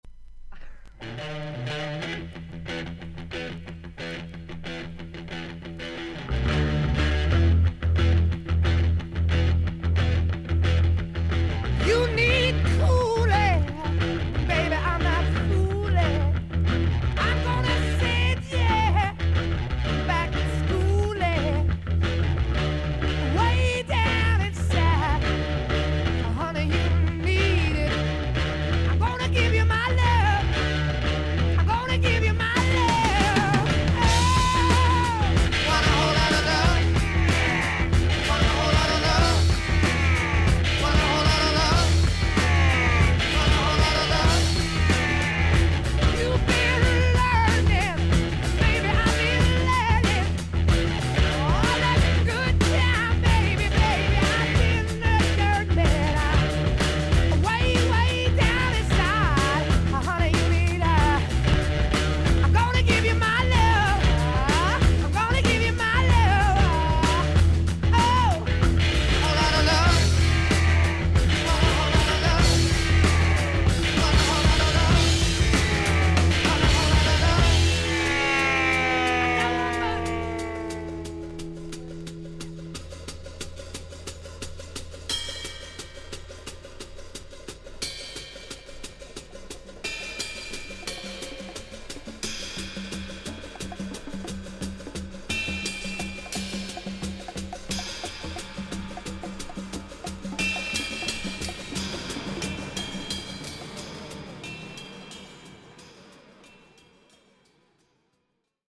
A1に斜めに1.2cmのキズ、それほど音に出ません。
ほかはVG++〜VG+:少々軽いパチノイズの箇所あり。クリアな音です。